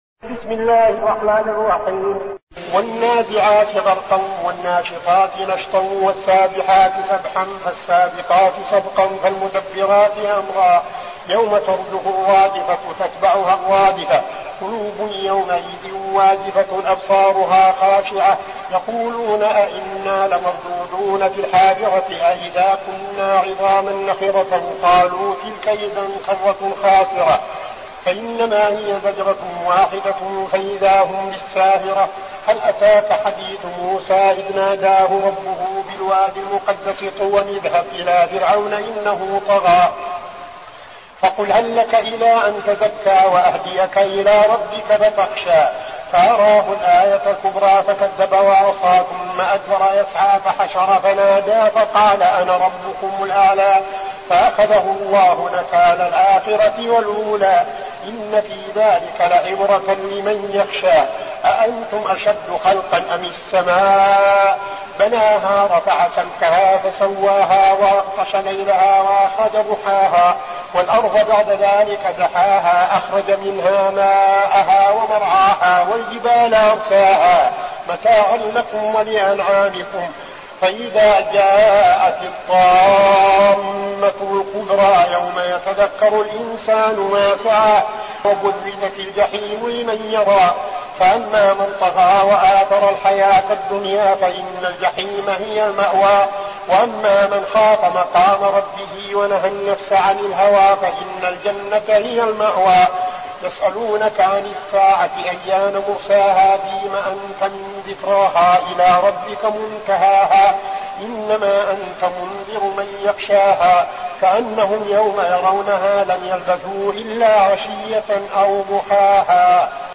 المكان: المسجد النبوي الشيخ: فضيلة الشيخ عبدالعزيز بن صالح فضيلة الشيخ عبدالعزيز بن صالح النازعات